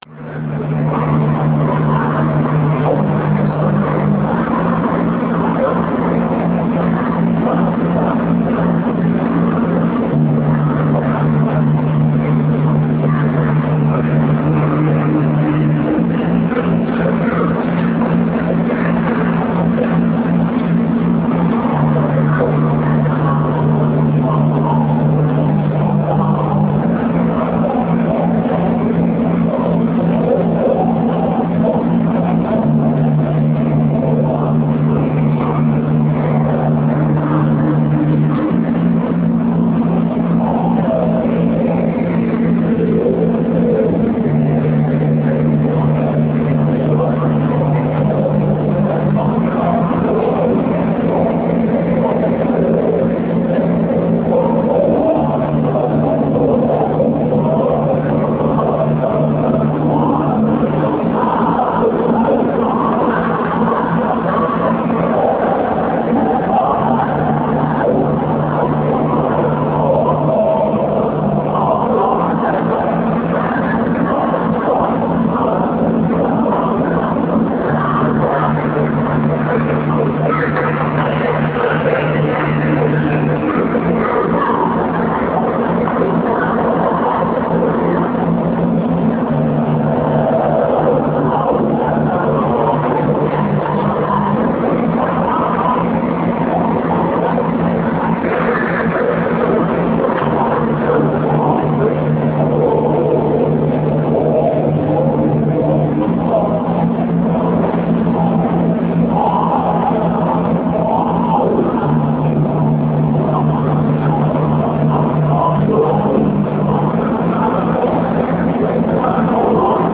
aufnahmen innerhalb der magazine show (can solo projects mit holger czukay) in köln 1999